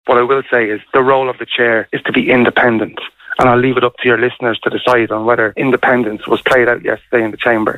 Speaking on Kildare Today, Social Democrats and Kildare North TD Aidan Farrelly said he does not want to "individualise" one person but he will leave it up to Kfm listeners to "decide" on whether or not Ceann Comhairle Verona Murphy acted independently yesterday.